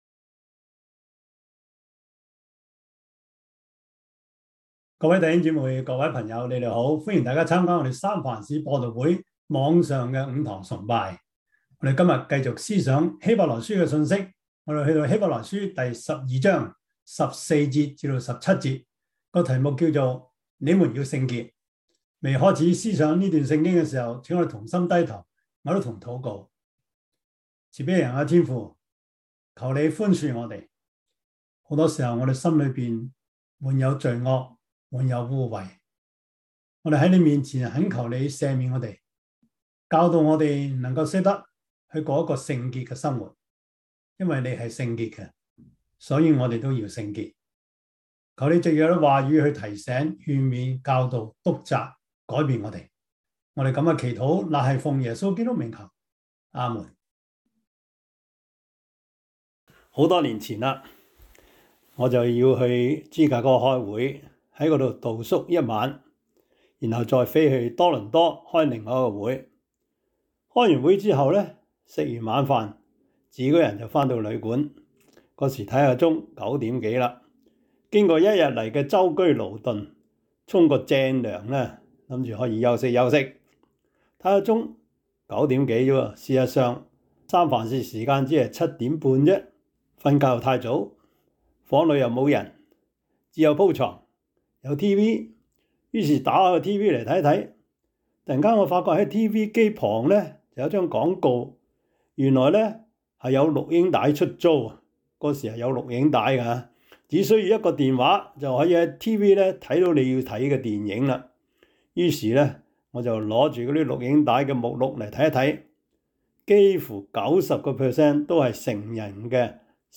希伯來書 12:14-17 Service Type: 主日崇拜 希 伯 來 書 12:14-17 Chinese Union Version